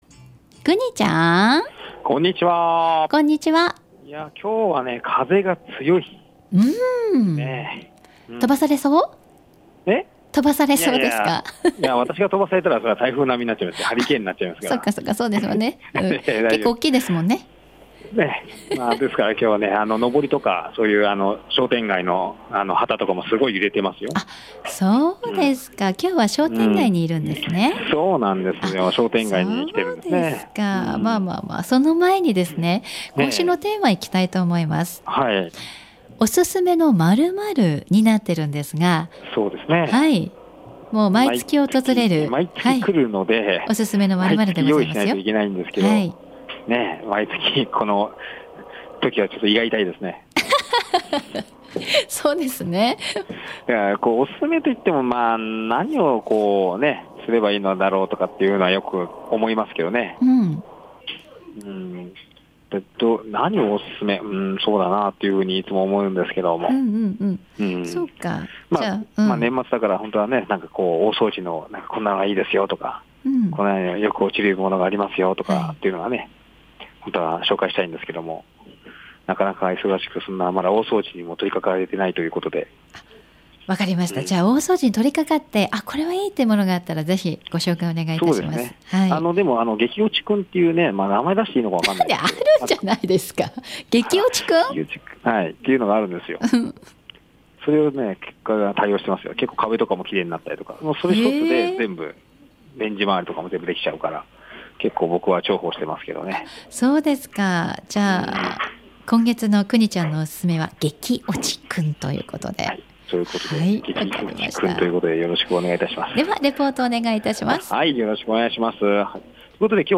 午後のカフェテラス 街角レポート
今年は土曜・日曜開催ということで 会場設営中の中お邪魔するというなんとも ほんとにお邪魔な中継になりました。